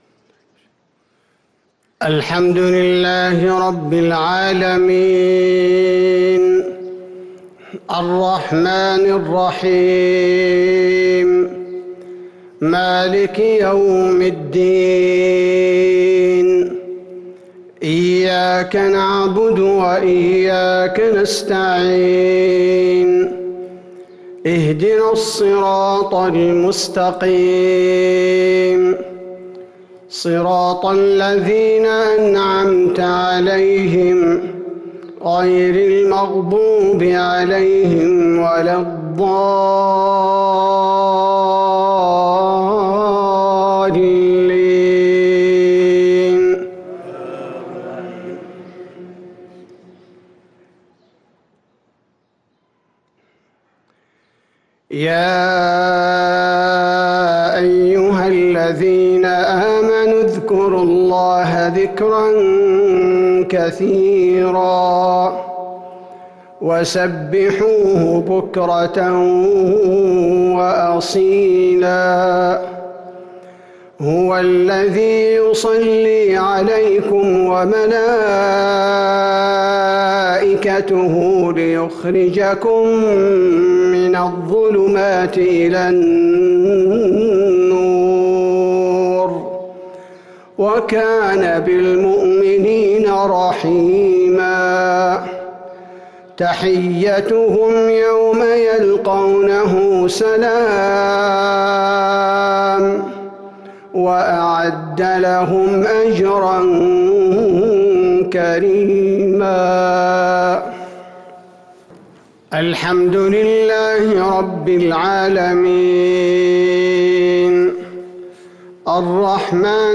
مغرب ٣-٧- ١٤٤٣هـ سورة الأحزاب | Maghrib prayer from Surah al-Ahzab 4-2-2022 > 1443 🕌 > الفروض - تلاوات الحرمين